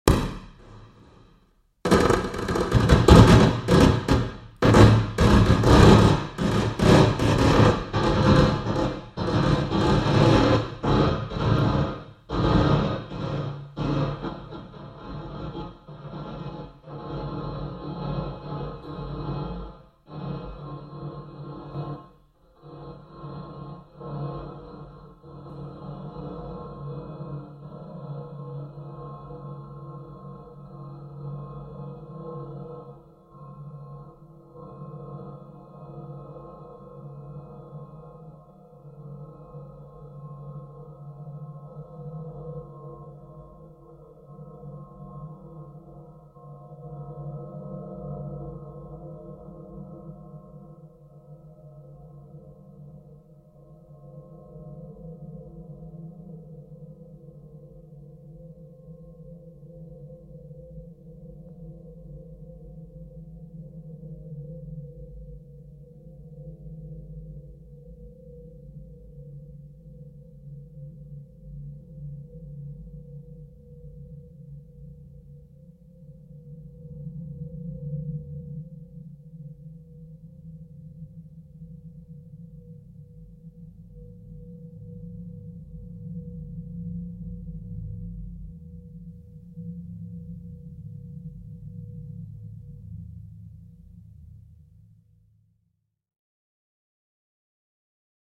impulsantwort.mp3